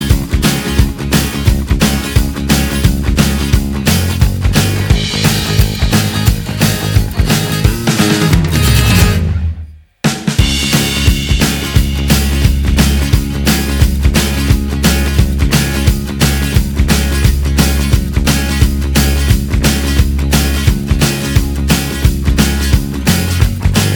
Minus All Guitars Except Acoustics Rock 4:04 Buy £1.50